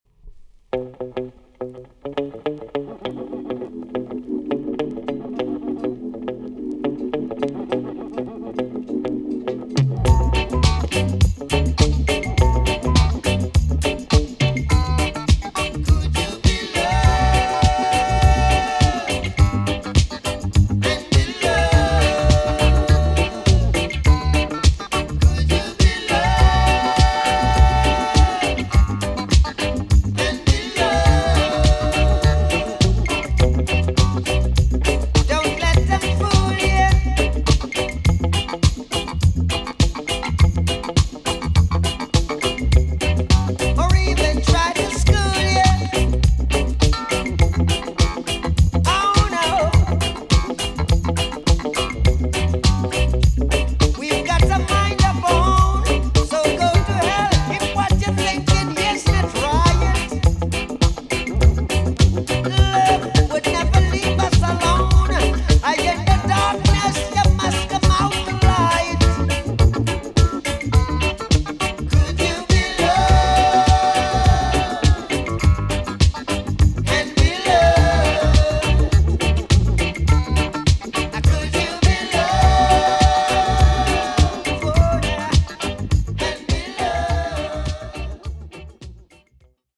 TOP >LP >VINTAGE , OLDIES , REGGAE
B.SIDE EX 音はキレイです。